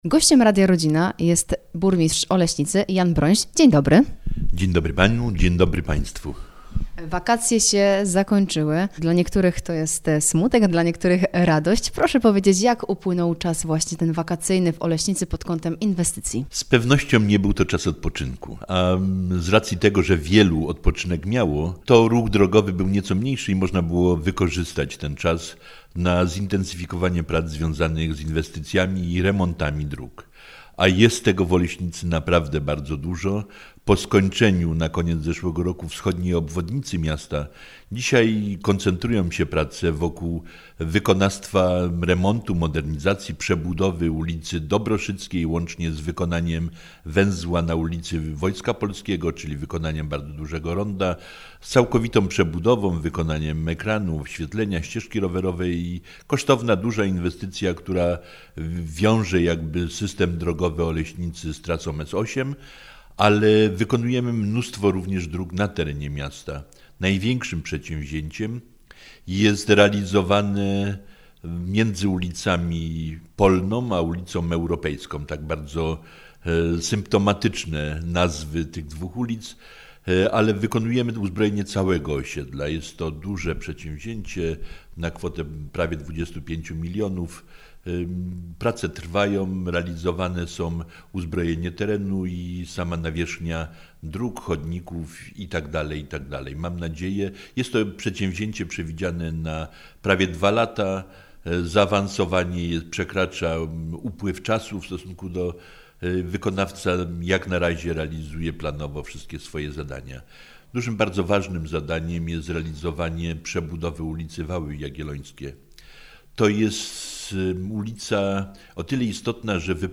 Rozmowa z burmistrzem Oleśnicy - Radio Rodzina
Rozmowa-z-burmistrzem-Olesnicy-Janem-Bronsiem-IB.mp3